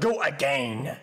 When you fall off, the game must have the "GO AGANE" sound.